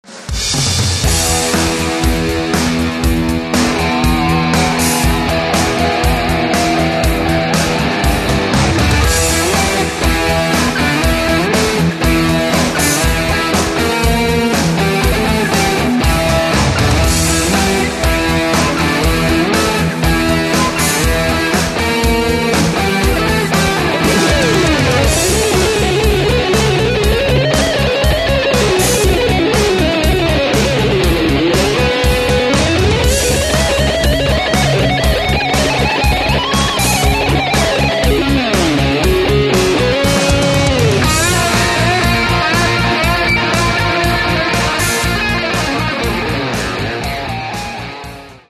Catalogue -> Rock & Alternative -> Simply Rock